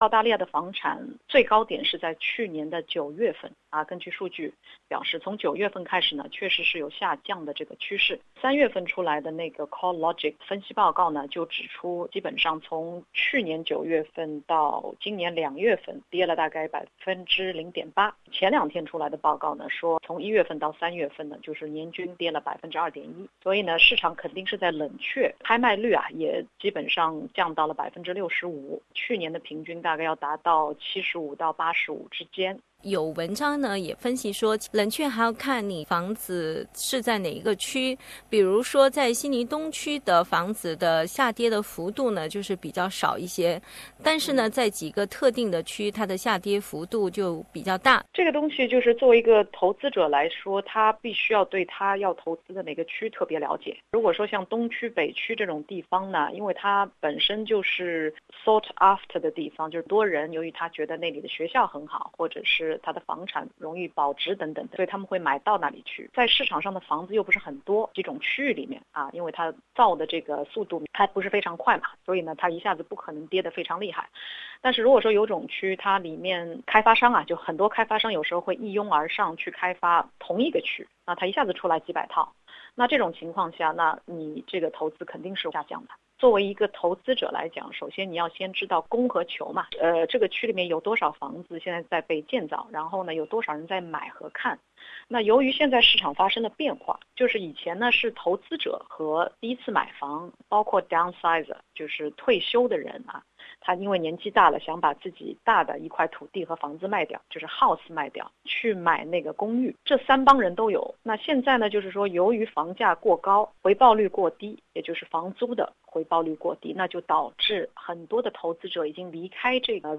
（嘉宾观点，仅供参考。）